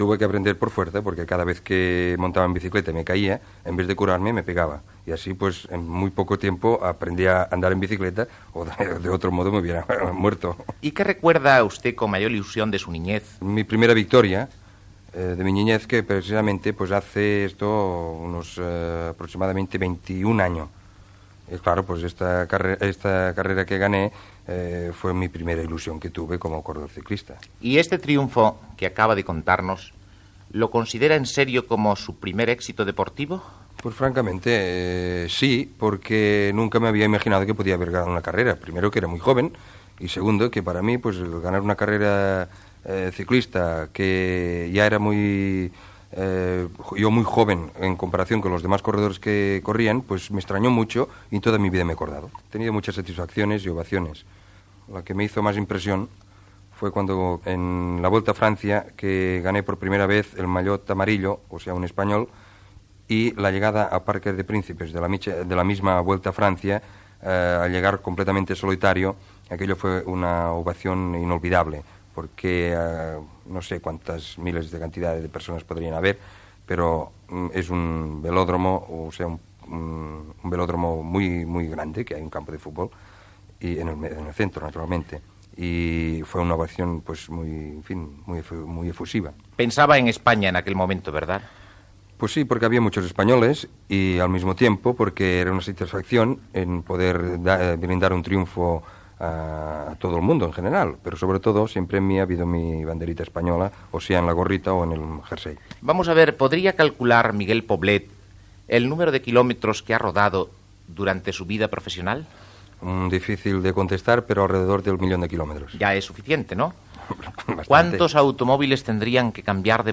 Entrevista al ciclista Miquel Poblet, qui parla sobre com va començar en el ciclisme i dels seus èxits com a professional
Esportiu
Fragment extret del programa "Audios para recordar" de Radio 5 emès el 7 de juliol del 2014.